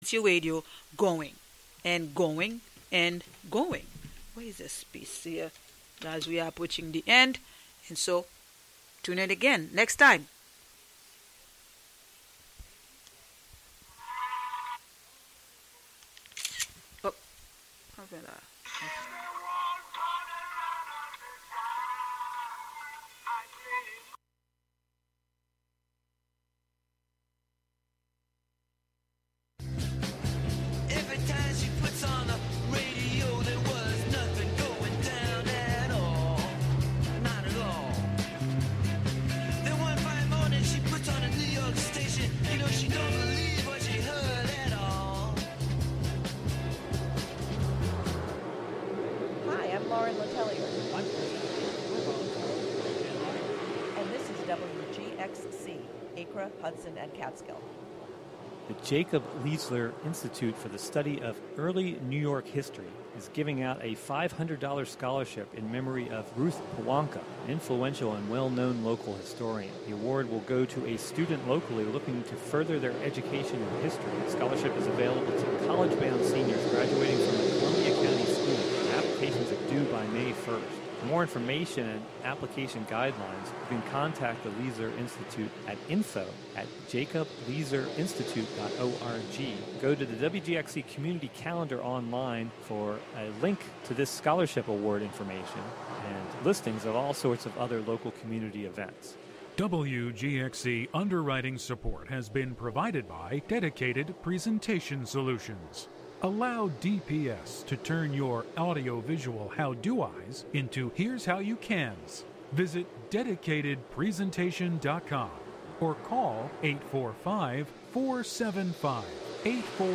On this show you will hear songs and sounds from a variety of genres as well as from unclassifiable styles of music and experimentation. The show will sometimes feature live performances from near and far and periodically unpredictable guests will join to share music.